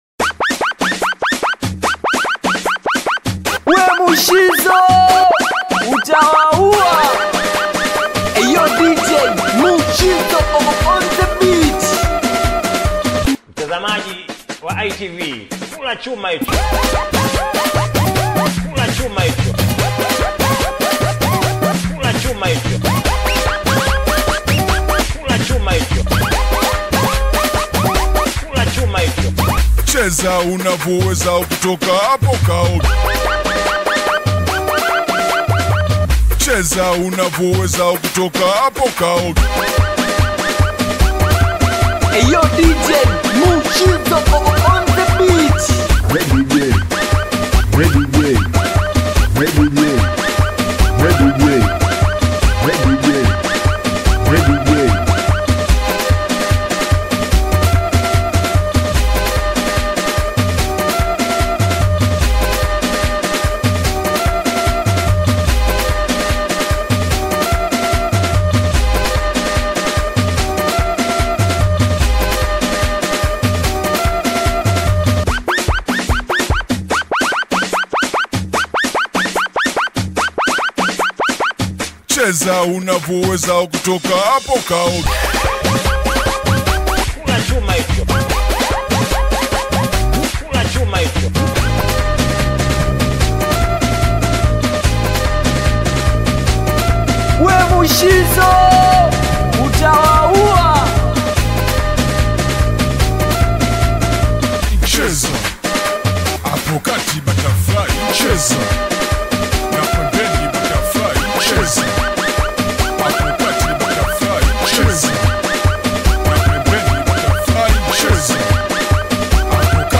Singeli
African Music